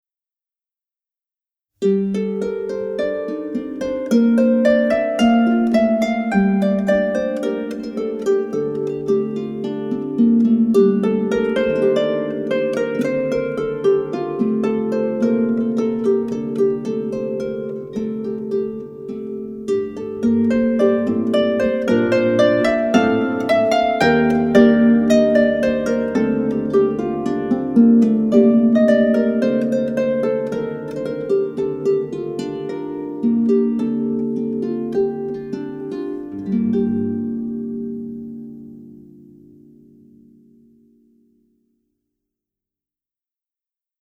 In 2016, MN made audio-visual recordings in the ITMA studio of ten of Ireland’s leading contemporary harpers giving solo performances of the 66 tunes from the first 1797 publication.